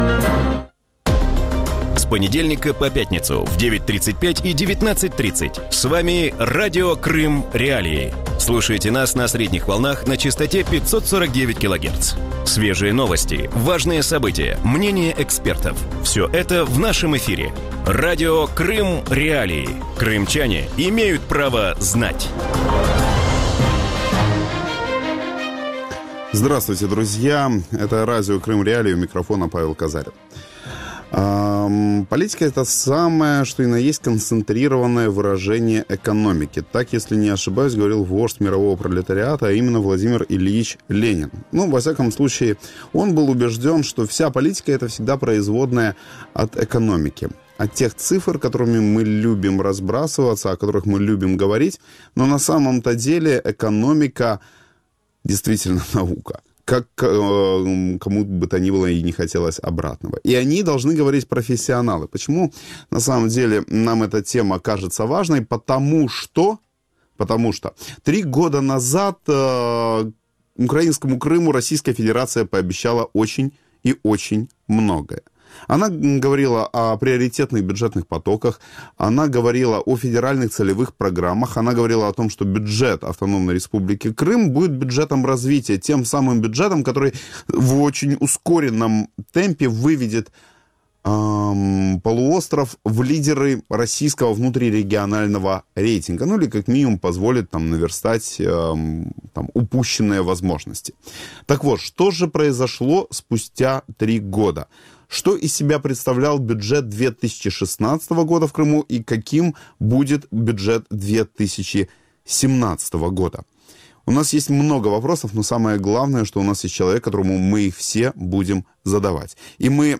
В вечернем эфире Радио Крым.Реалии говорят об исполнении крымского бюджета за 2016 год. Чем аннексированный Крым отличается от российских регионов по структуре финансирования, на что тратят деньги подконтрольные Кремлю власти полуострова и есть ли перспективы развития у региона?